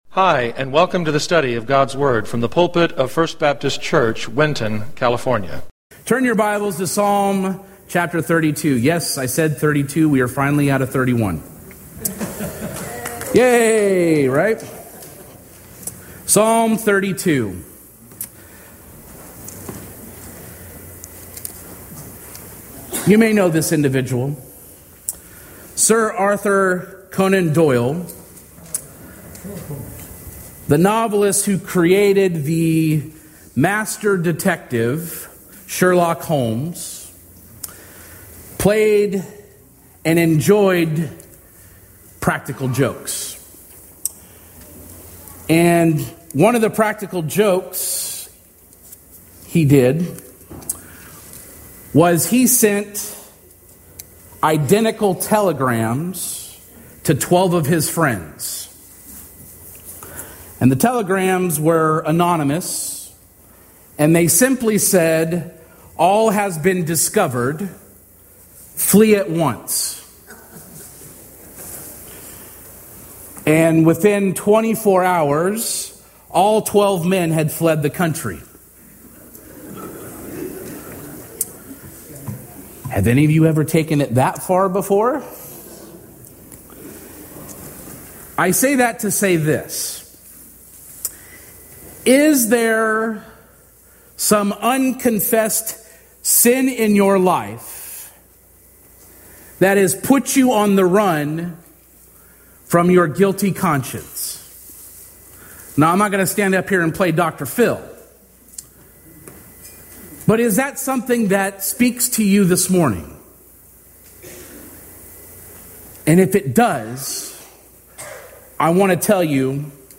Sermons | Winton First Baptist Church